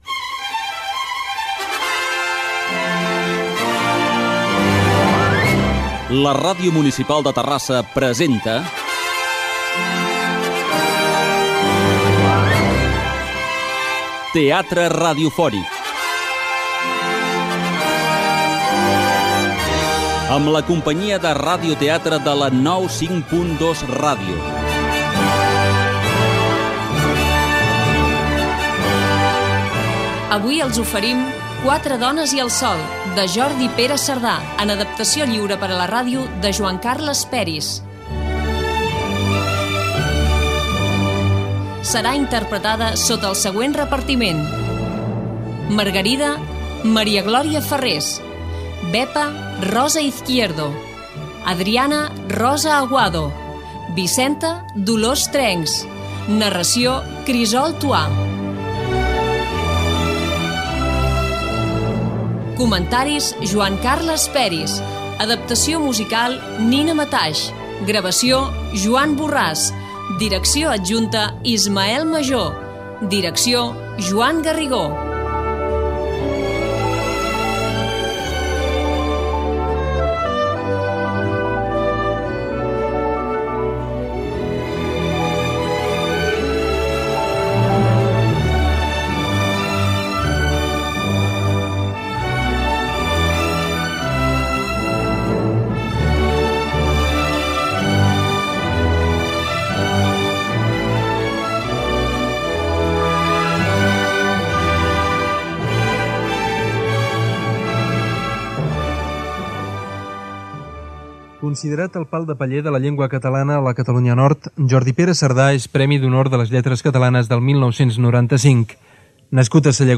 Teatre radiofònic
Versió radiofònica de l'obra"Quatre dones i el sol" de Jordi Pere Cerdà.
Careta, repartiment, comentari de l'obra i fragment del seu inici.